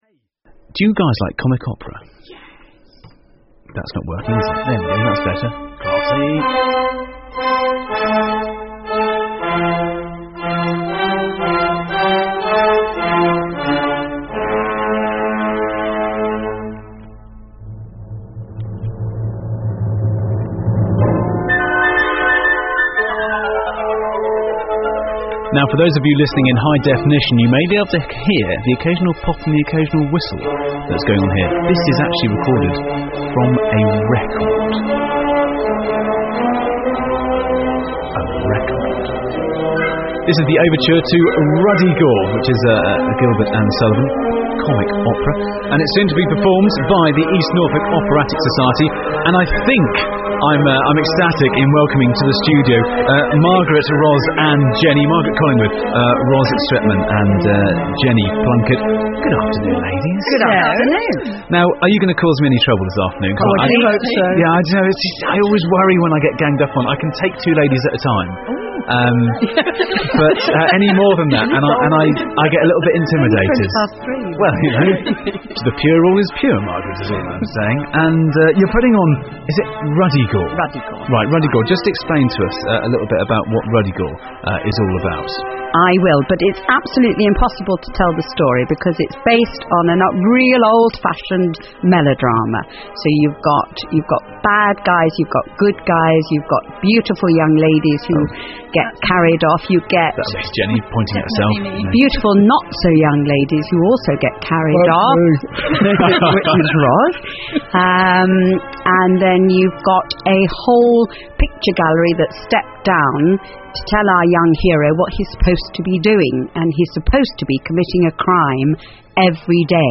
Documents Click on image Programme Cover Click on image Publicity Poster Ruddigore Synopsis Press Review NODA review Listen to the Publicity Interview broadcast on BBC Radio Norfolk on 27 April 2010